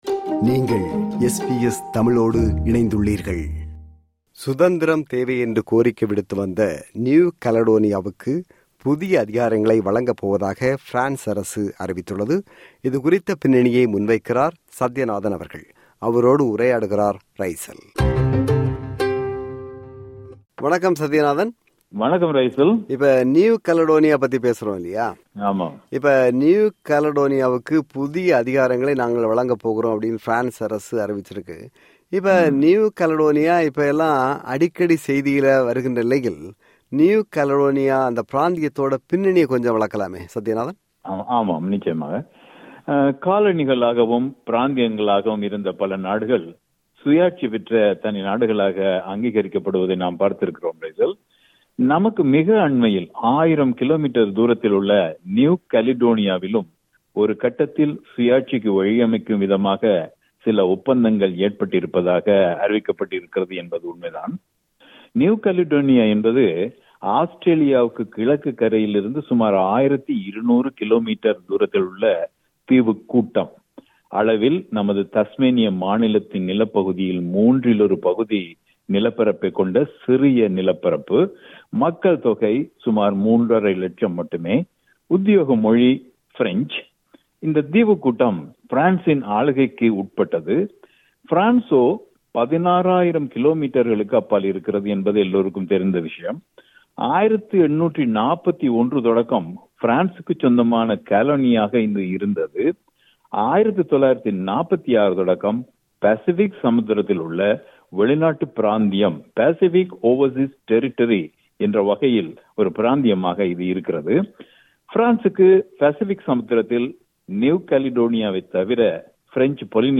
SBS தமிழின் ஏனைய நிகழ்ச்சிகளைக் கேட்க எமது podcast பக்கத்திற்குச் செல்லுங்கள்.